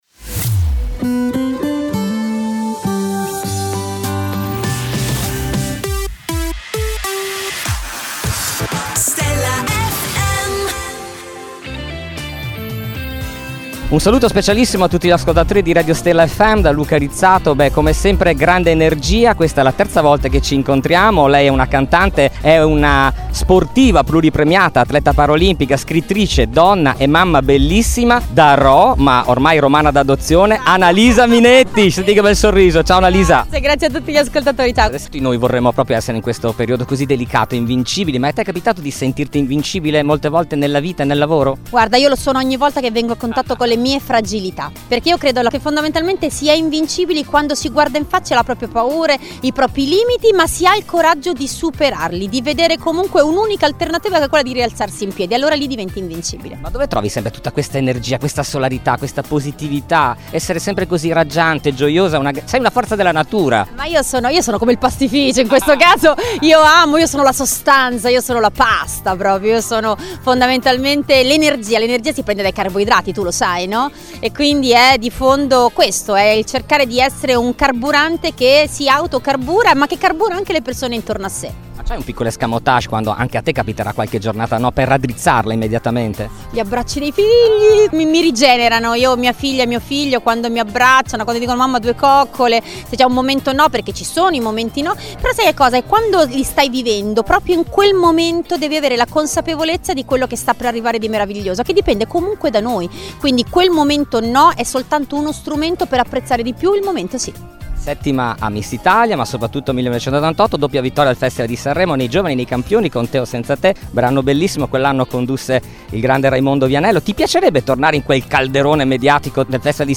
Intervista I Annalisa Minetti | Stella FM
Intervista esclusiva dell’inviato per Stella FM a Annalisa Minetti.